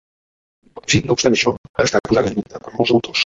Pronúnciase como (IPA) [puˈza.ðə]